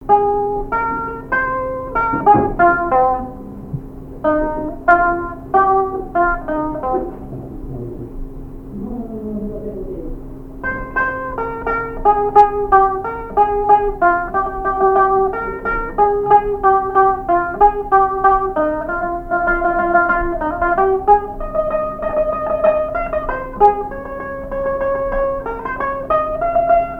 Air de music-hall
répertoire au violon et à la mandoline
Pièce musicale inédite